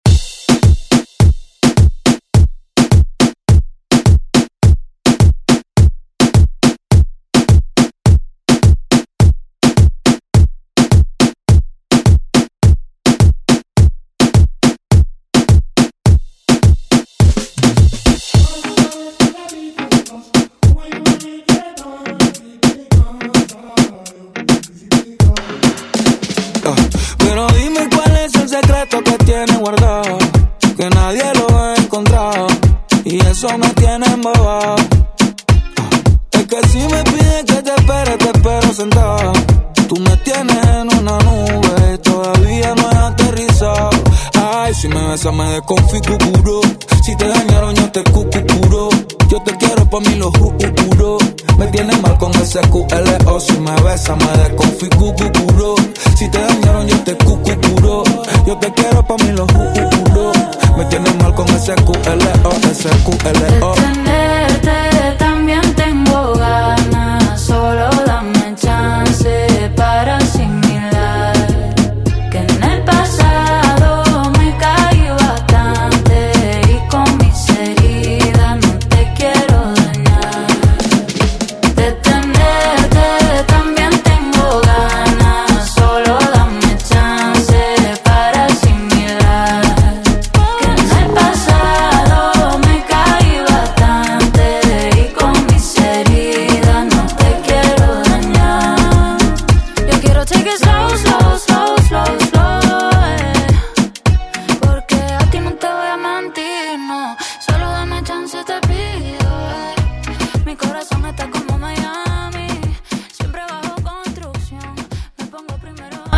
Classic Pop Rock Music
96 bpm
Genres: 70's , RE-DRUM , ROCK
Clean BPM: 96 Time